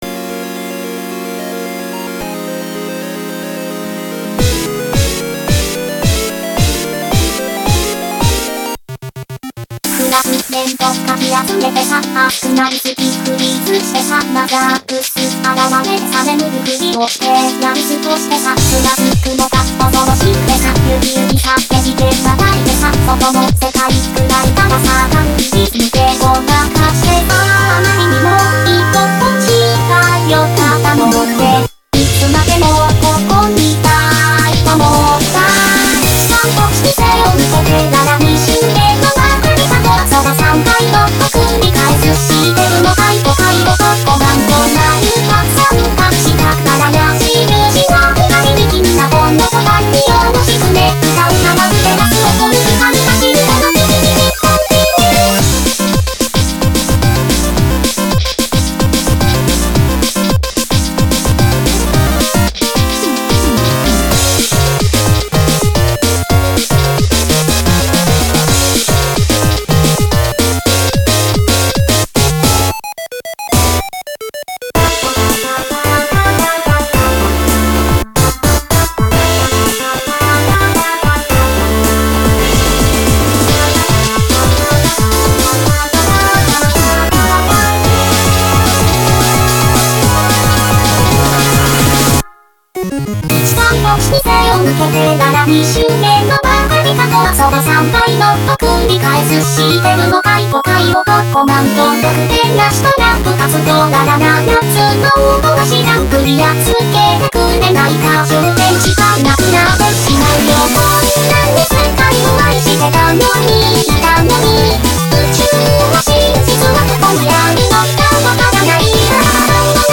BPM110-220
Audio QualityPerfect (Low Quality)